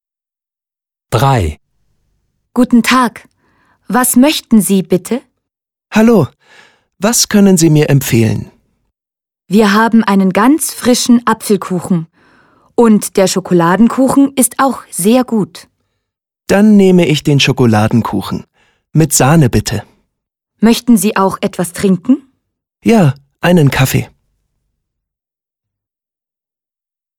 Dialog 3: